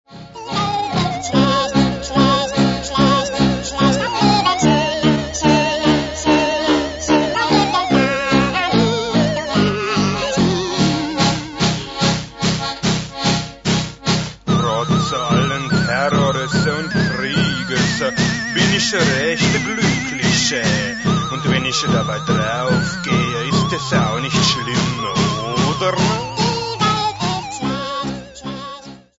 Stilbildend und vorbildlich für Analog und Lo-Tec-Fans.